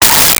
Space Gun 14
Space Gun 14.wav